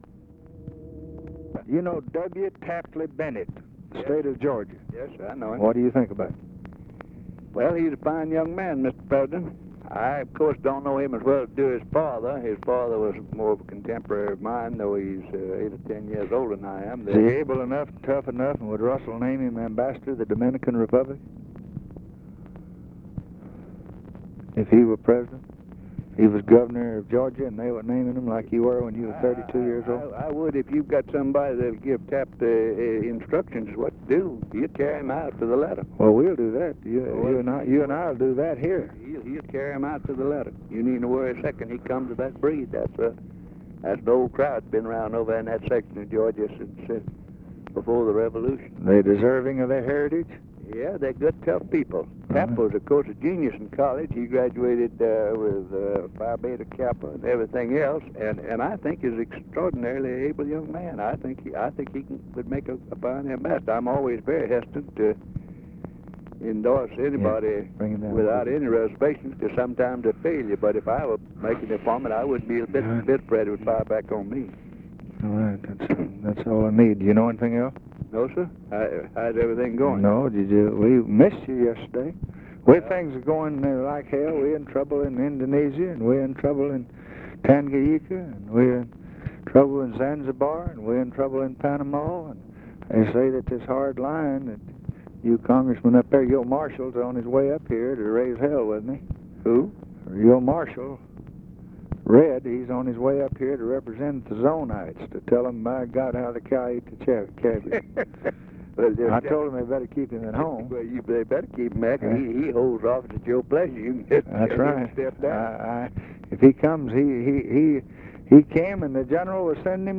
Conversation with RICHARD RUSSELL, January 20, 1964
Secret White House Tapes